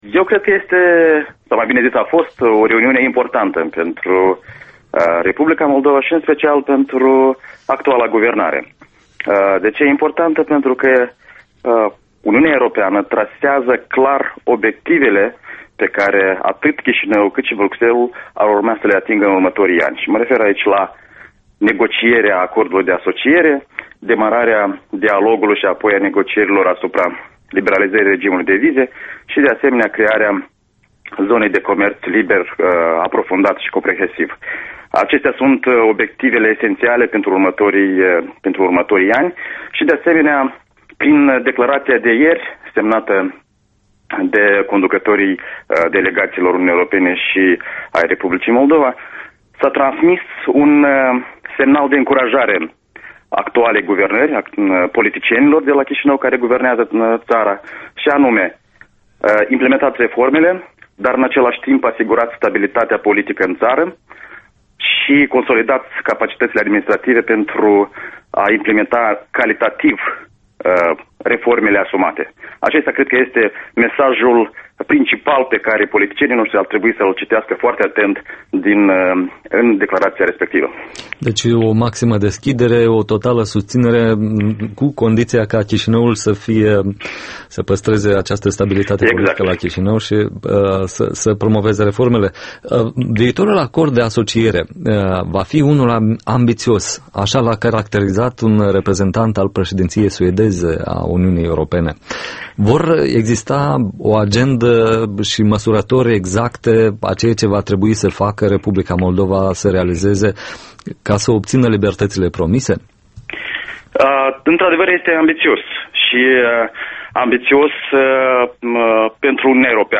Embed Interviul matinal